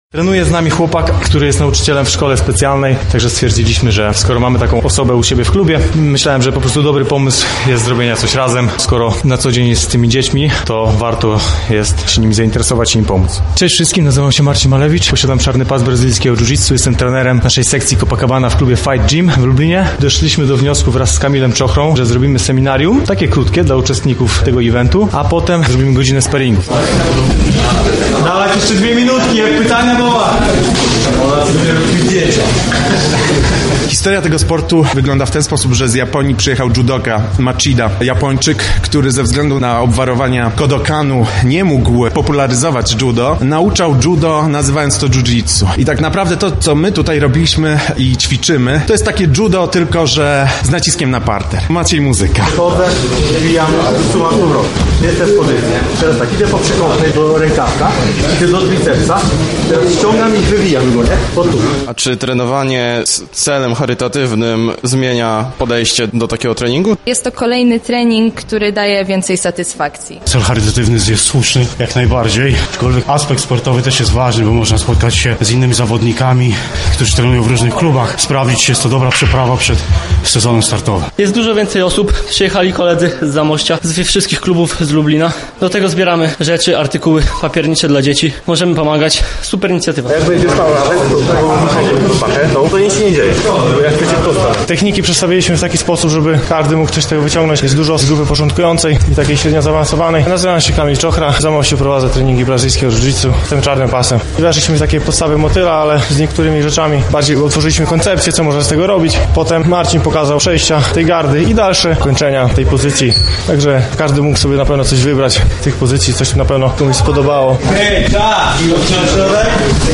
O pomyśle na wydarzenie czy przebiegu seminarium opowiedzieli organizatorzy, a także sami uczestnicy.